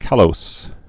(kălōs)